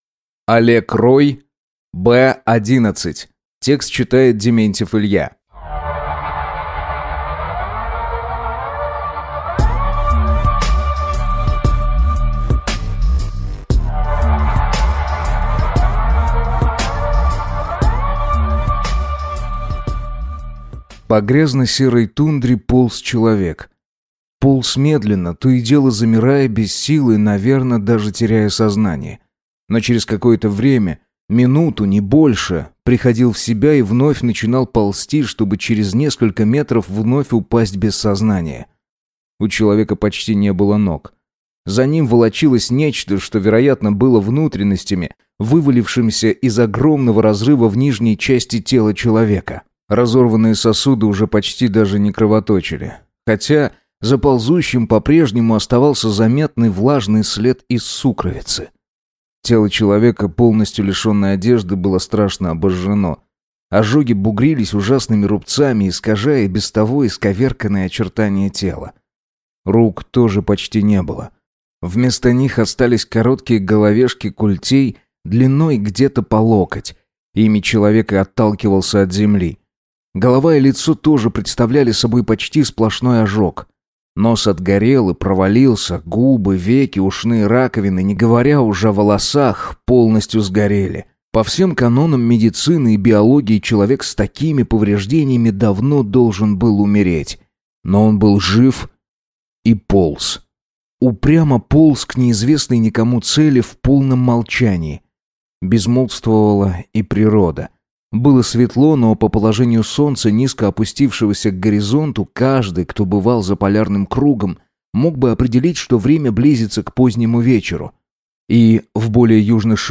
Аудиокнига Б-11 | Библиотека аудиокниг